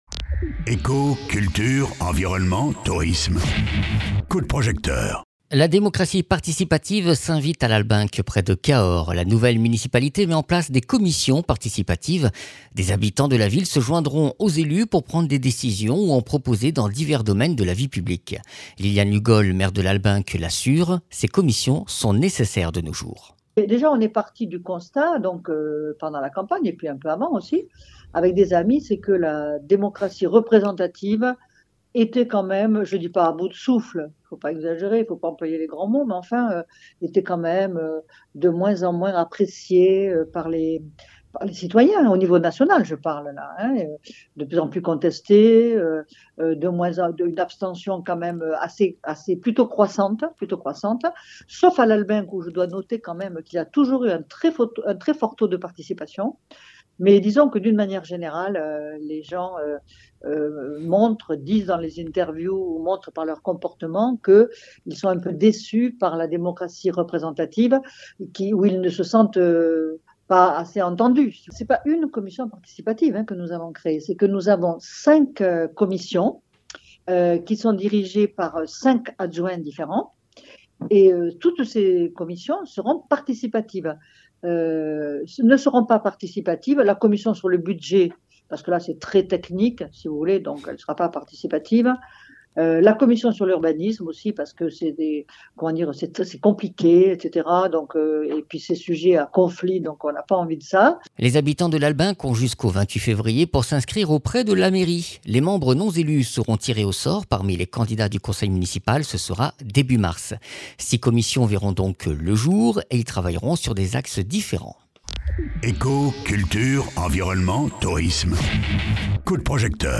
Interviews
Invité(s) : Liliane Lugol, Maire de Lalbenque (Lot)